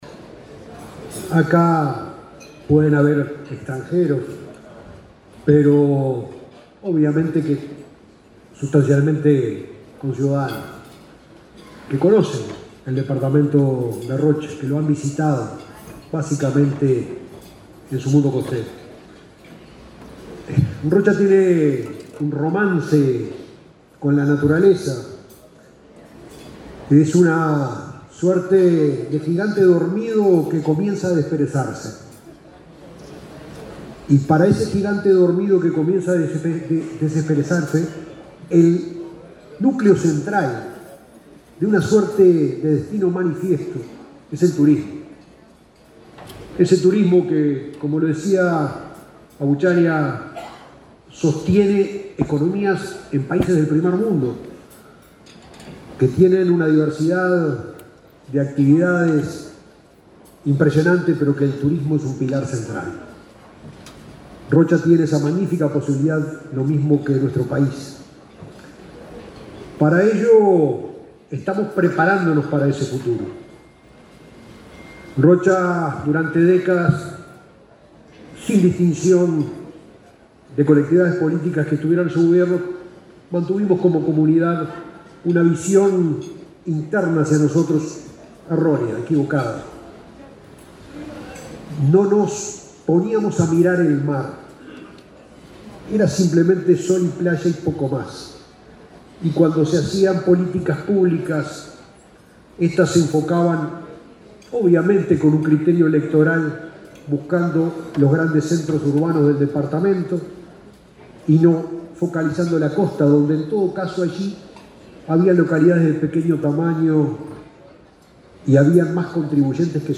Disertación del intendente de Rocha, Alejo Umpiérrez
El intendente de Rocha, Alejo Umpiérrez, disertó, este jueves 7 en Montevideo, en un almuerzo de trabajo de la Asociación de Dirigentes de Marketing,